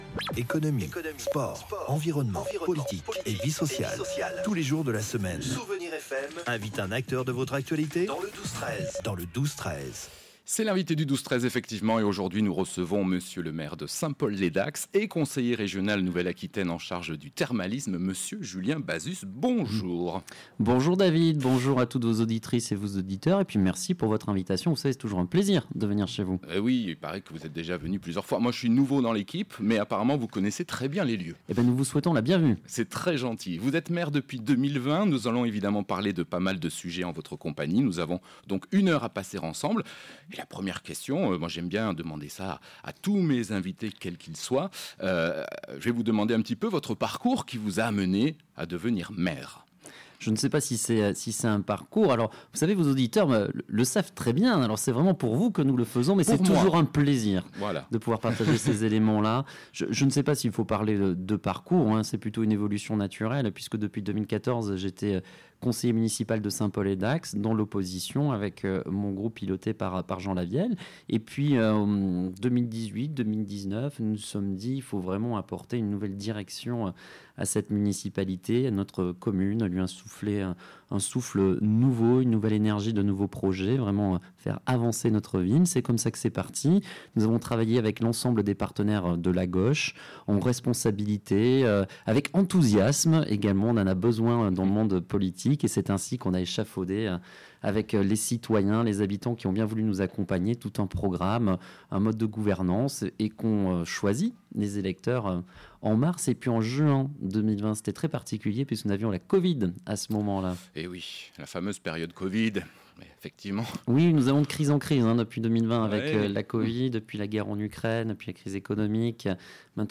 L'invité du 12-13 de Dax recevait M.le Maire de St Paul les Dax, Julien Bazus, également Conseiller Régional Nouvelle Aquitaine en charge du thermalisme.